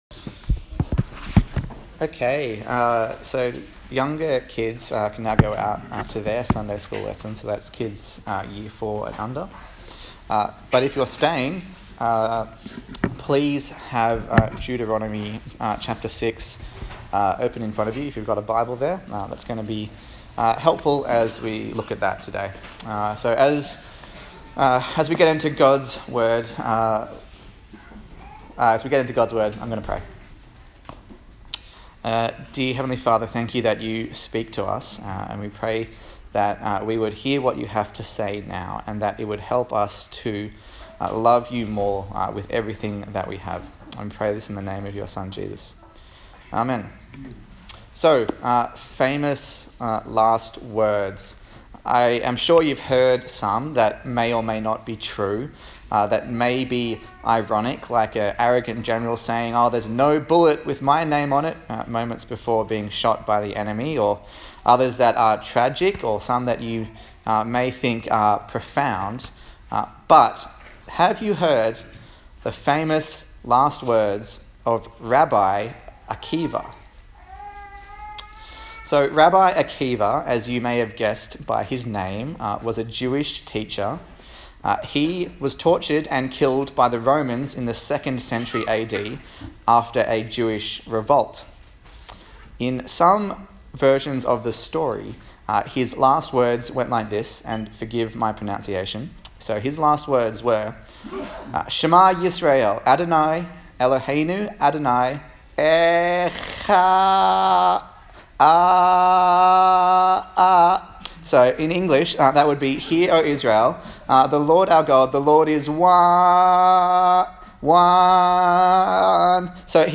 A sermon in the series on the book of Deuteronomy